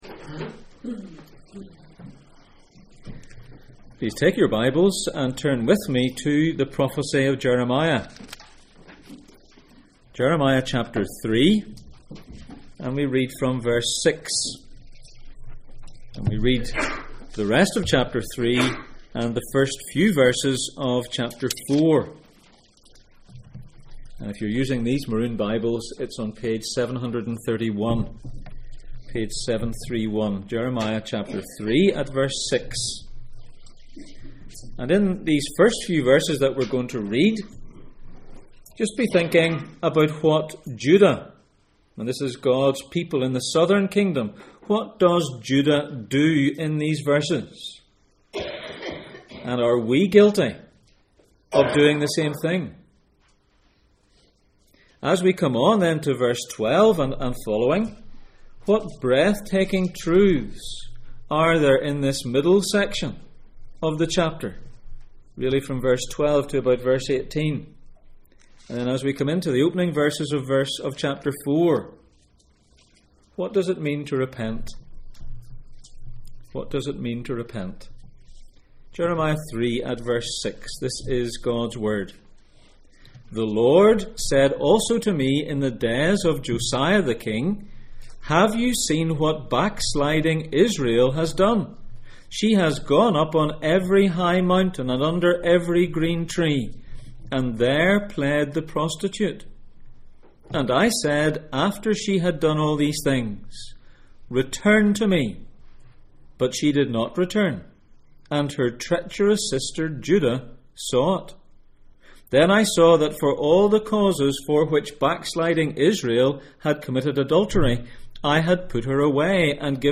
The highs and lows of Jeremiah Passage: Jeremiah 3:6-4:4 Service Type: Sunday Morning %todo_render% « Where false love brings you Agin Sin?